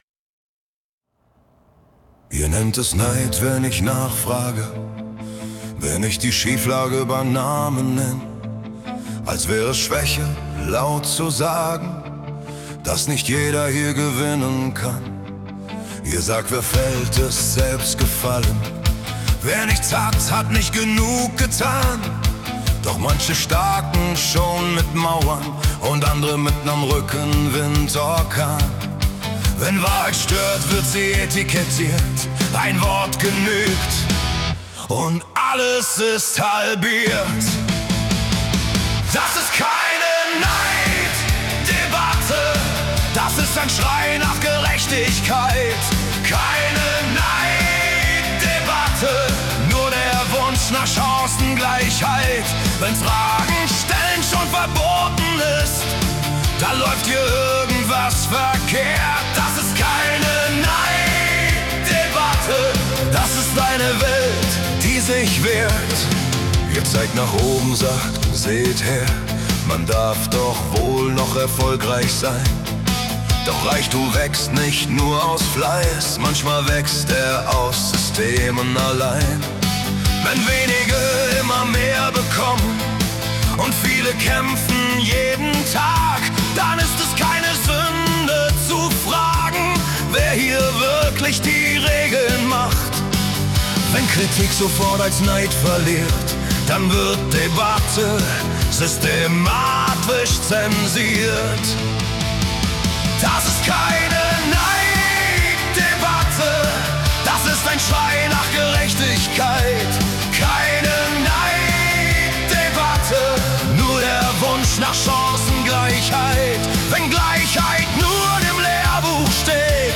🎸 Deutsch Rock / Rock Pop mit Haltung
Moderne Rockmusik trifft auf politische Themen wie: